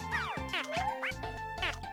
Pengoonnoise.ogg